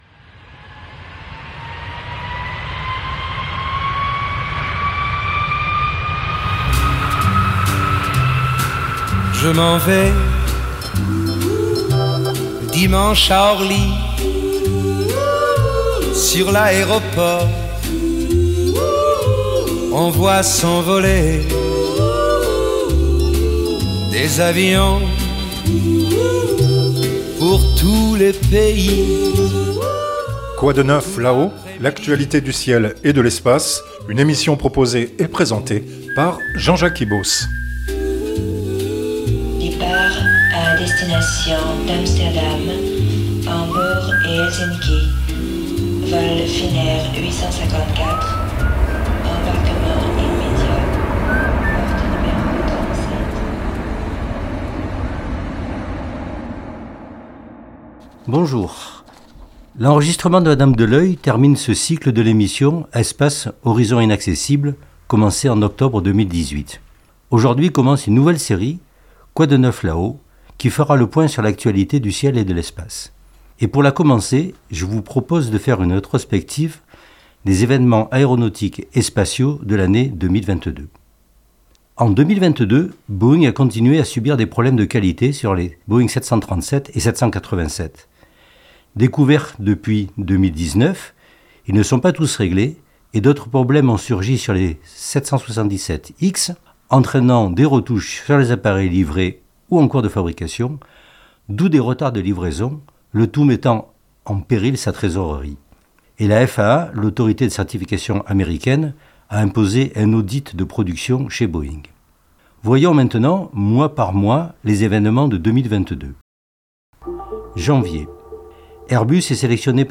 une émission proposée et présentée